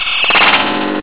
autocomplete_end.ogg